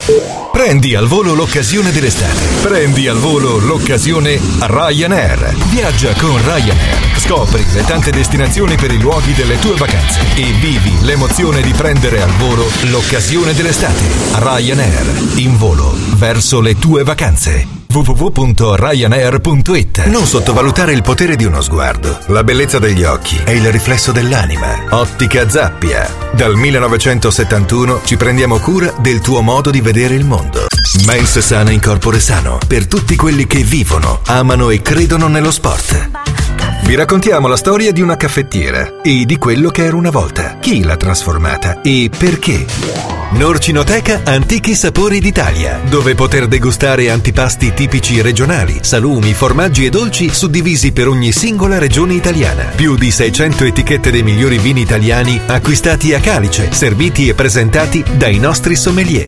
Speaker italiano madrelingua, voce calda e professionale, rassicurante e convincente.
Sprechprobe: Werbung (Muttersprache):
My voice is deep and warm, reassuring and convincing, ideal for narrations, commercials, smooth and professional for documentaries, multimedia project dvds, and many more.